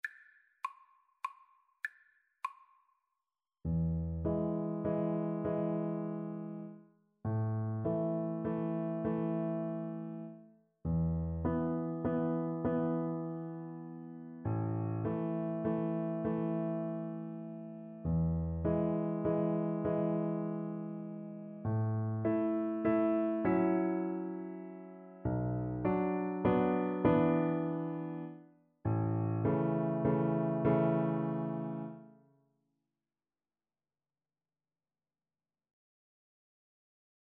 Piano Four Hands (Piano Duet) Classical Piano Four Hands
3/4 (View more 3/4 Music)
Classical (View more Classical Piano Duet Music)